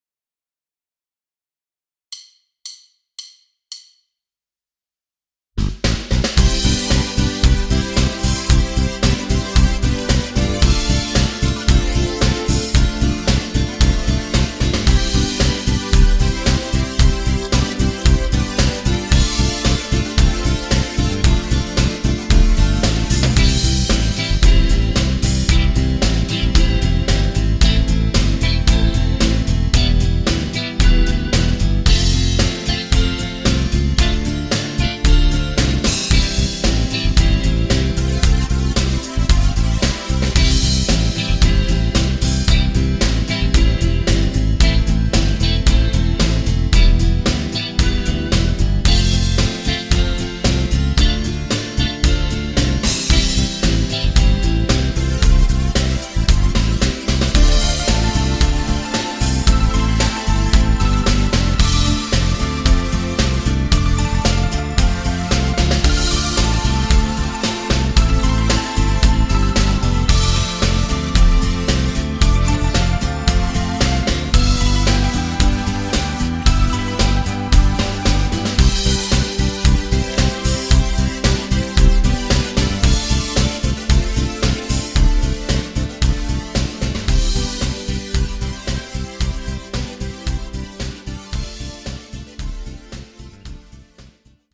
Playback, Instrumental, Karaoke